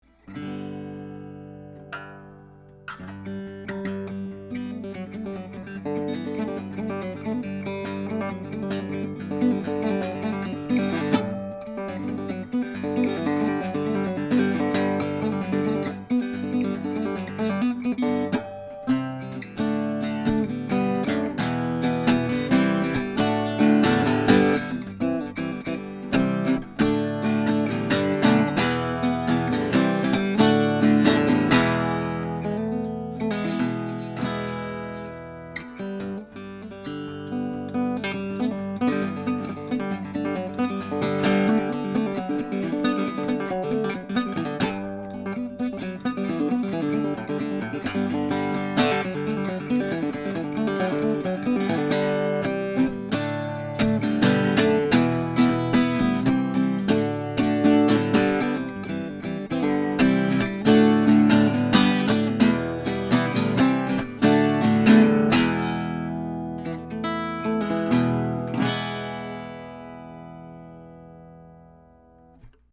Archtop
Guitar
The high end is accuentated, and the transients are quick.
I used a SAMAR/AKG C1000s condenser mic going into a Sony PCM D1 flash recorder, with no reverb, eq or any other effects.
4 | Country Song
(Original in E)